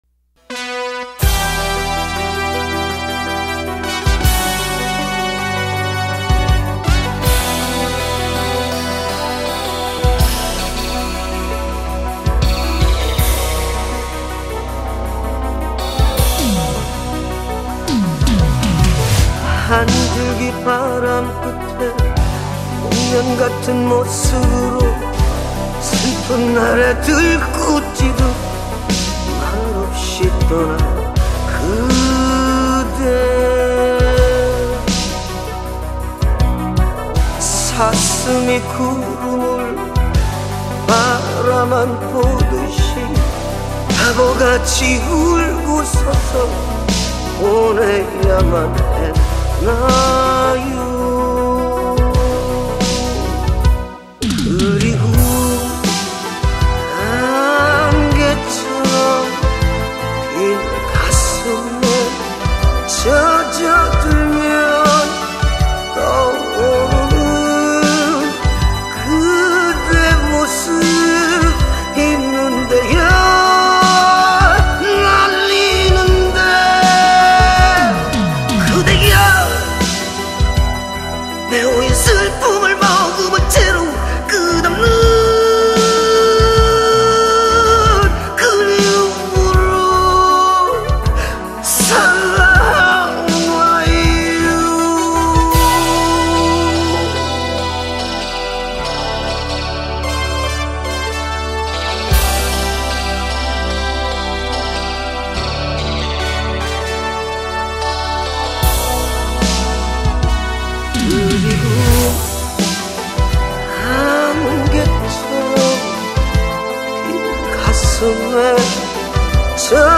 노래